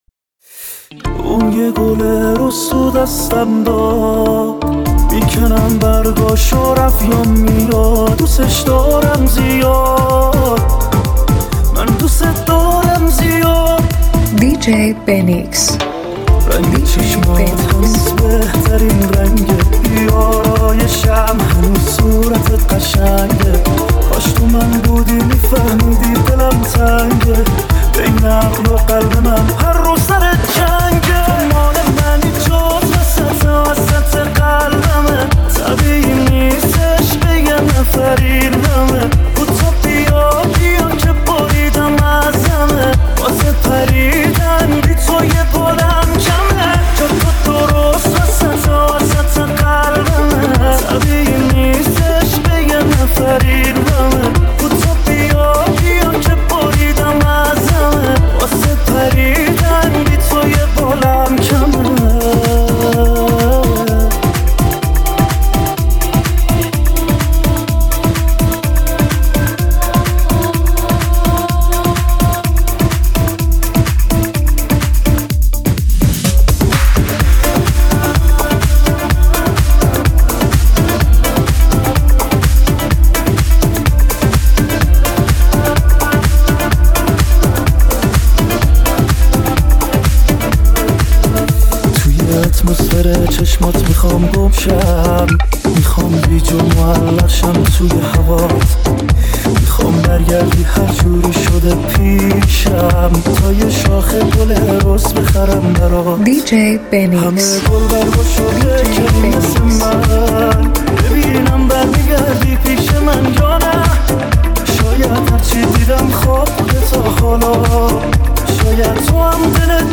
ضرب‌آهنگ‌های الکترونیک